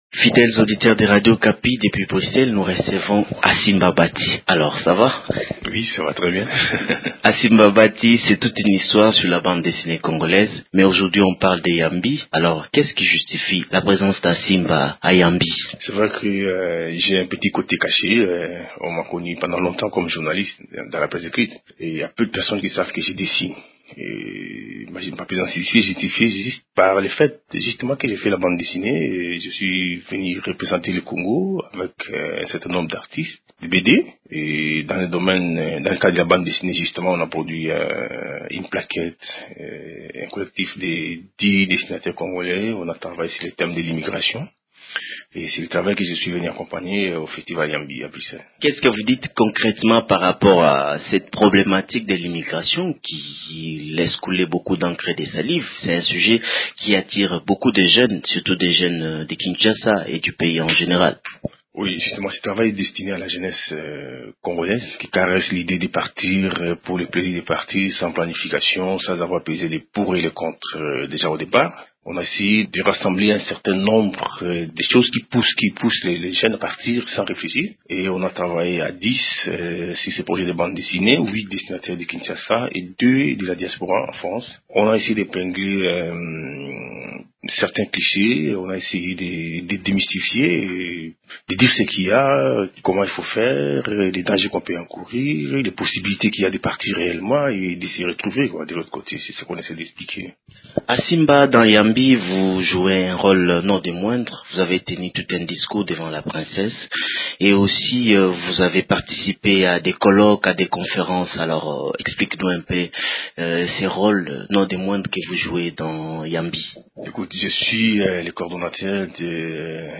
L’artiste s’exprime dans cet entretien